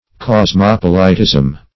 Search Result for " cosmopolitism" : The Collaborative International Dictionary of English v.0.48: Cosmopolitism \Cos*mop"o*li*tism\ (k?z-m?p"?-l?-t?z'm), n. The condition or character of a cosmopolite; disregard of national or local peculiarities and prejudices.
cosmopolitism.mp3